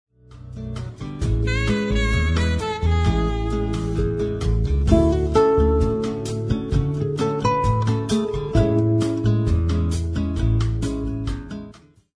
Instrumental Album of the Year